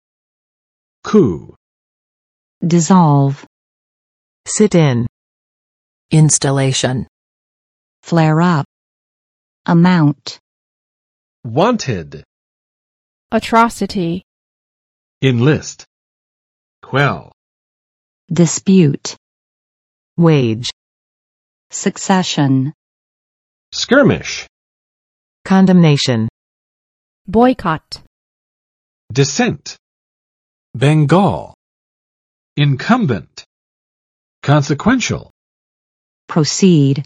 Vocabulary Test - April 12, 2019
[ku] n. 政变